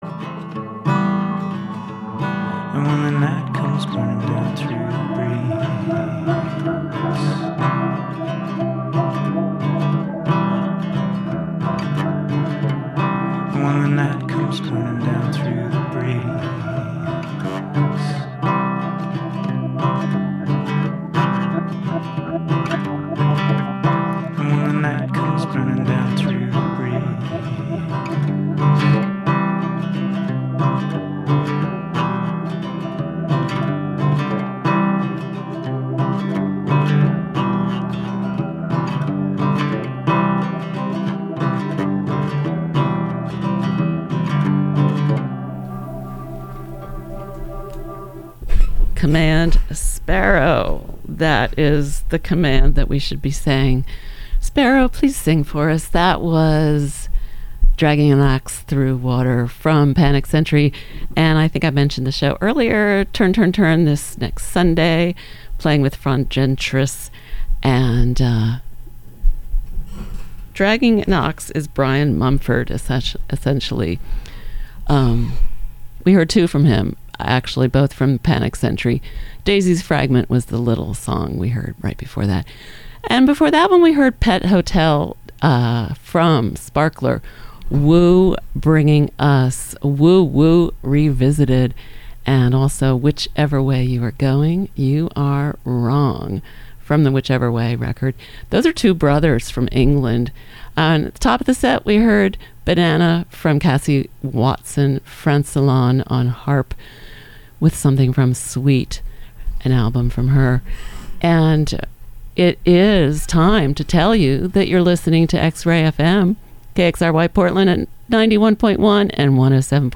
spins a variety mix of music from artists you know and love, as well as introducing you to some you don't.